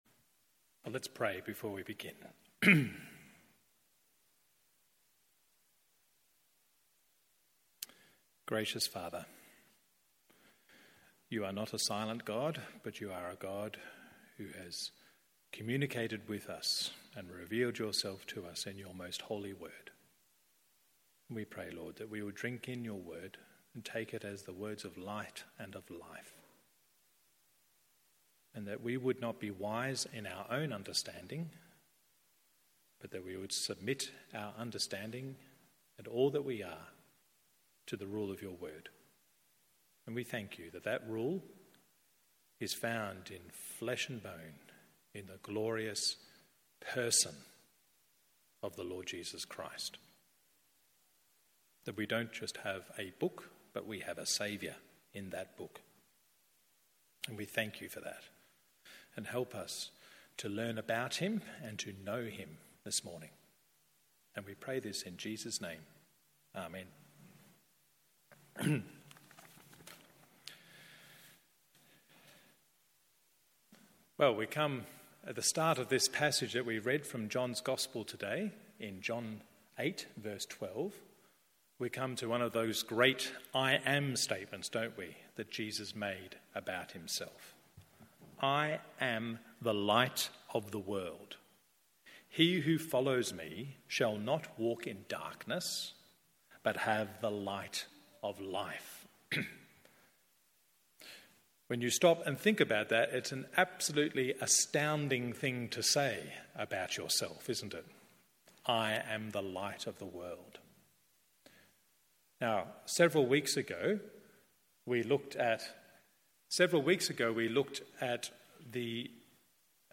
MORNING SERVICE John 8:12-30…